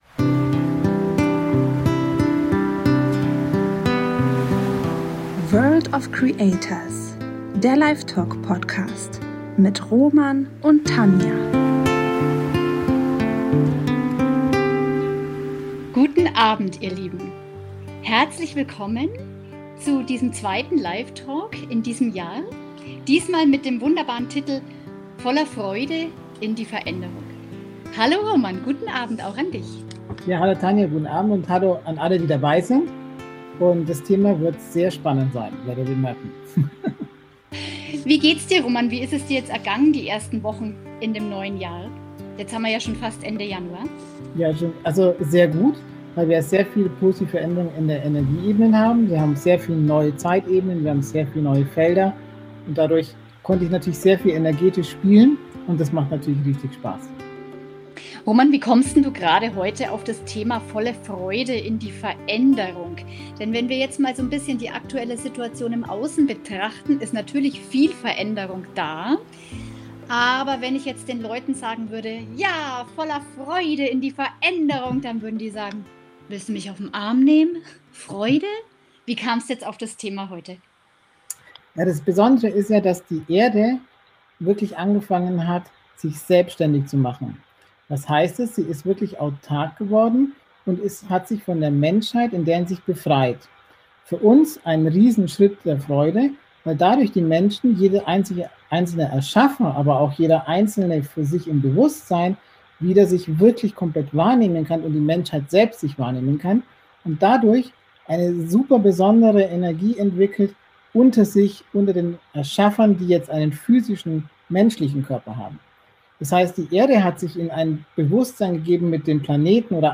Livetalk Thema: Voller freude in die Veränderung gehen